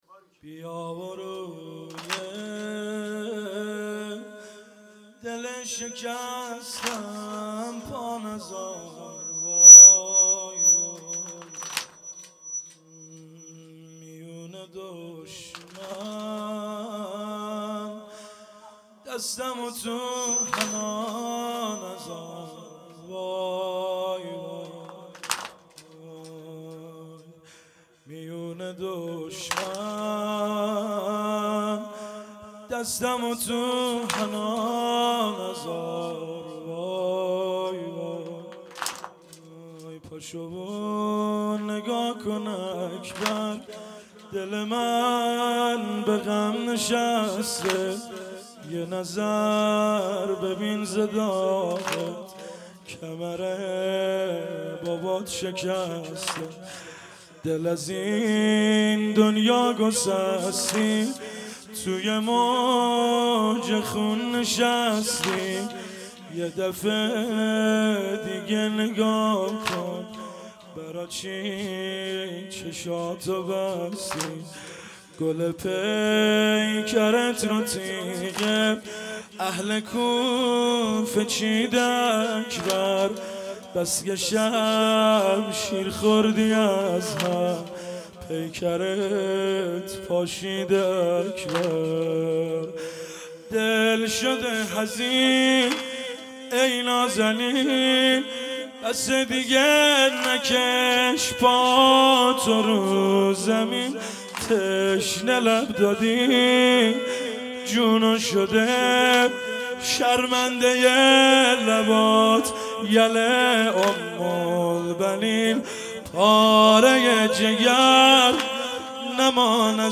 هیئت فرهنگی _ مذهبی مجمع الزهرا(س)